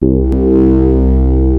OSCAR PAD 01 2.wav